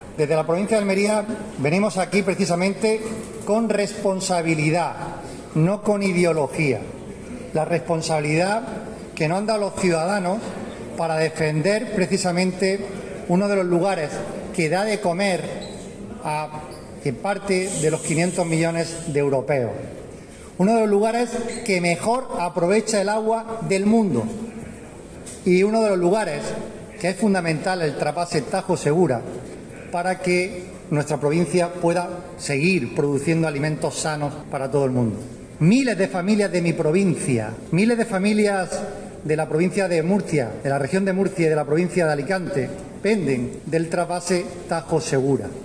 Esta mañana en el Cuartel de Artillería de Murcia, en un acto organizado por el Ayuntamiento capitalino, se han congregado un centenar de alcaldes de las provincias de Almería, Alicante y Murcia para firmar un manifiesto y alzar su voz contra el recorte de agua del Trasvase Tajo – Segura previsto por el Gobierno de España con la reducción de 7,5 hm3.